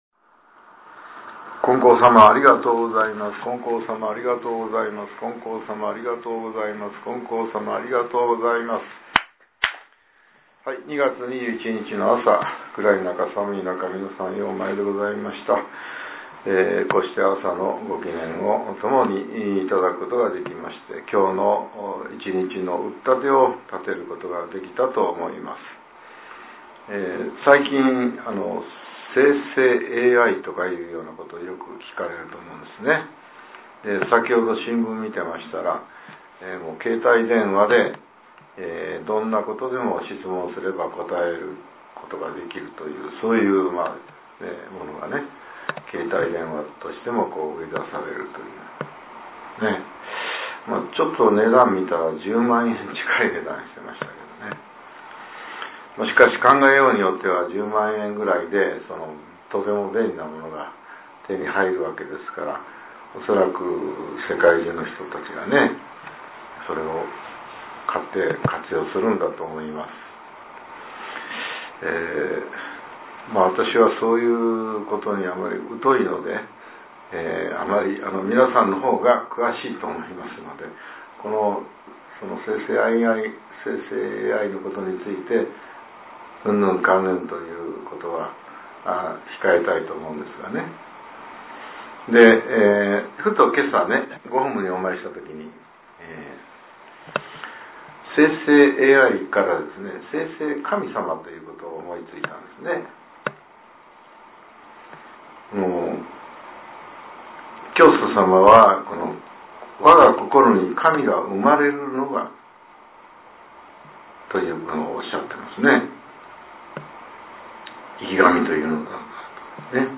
令和７年２月２１日（朝）のお話が、音声ブログとして更新されています。